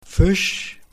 Ääntäminen
Ääntäminen Tuntematon aksentti: IPA: /fɪʃ/ Haettu sana löytyi näillä lähdekielillä: saksa Käännös Ääninäyte Substantiivit 1. fish UK US Artikkeli: der .